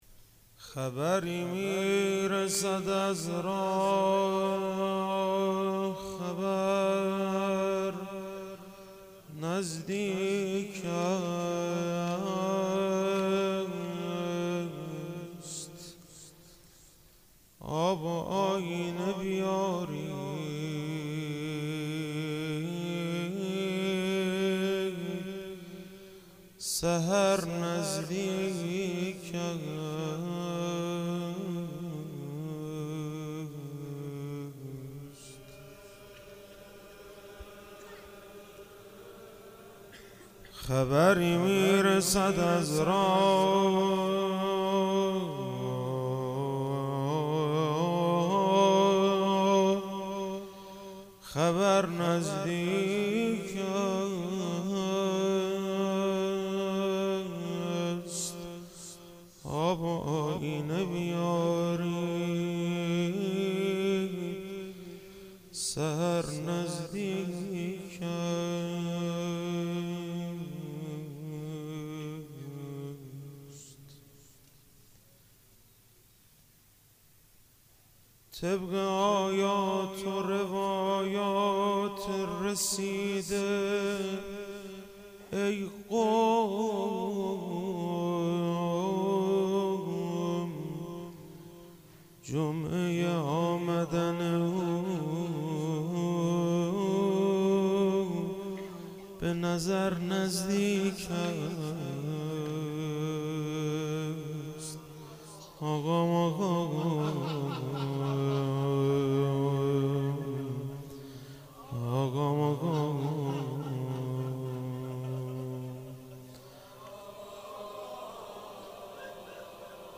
روضه - خبری میرسد از راه خبر